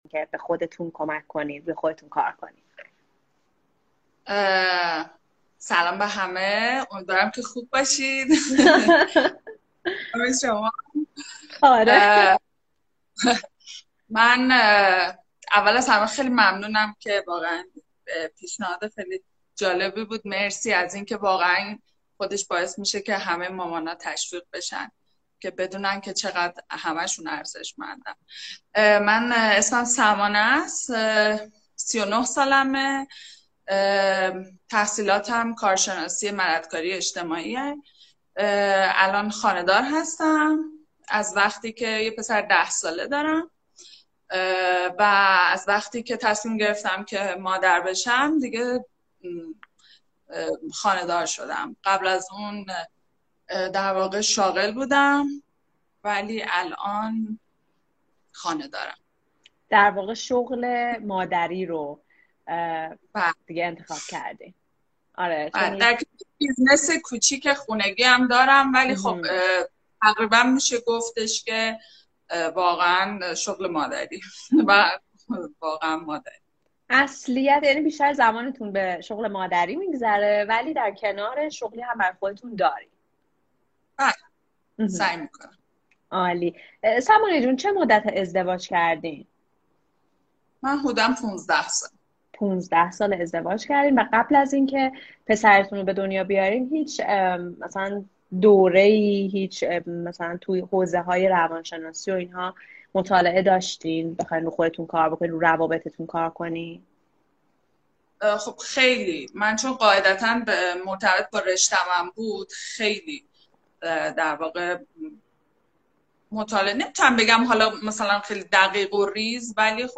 مصاحبه با مادر بالنده ۱